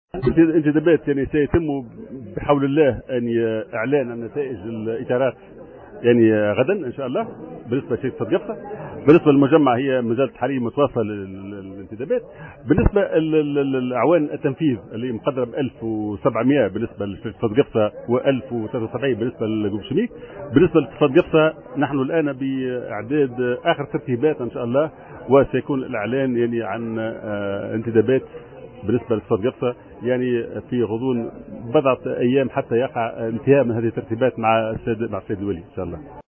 تصريح
الندوة الصحفية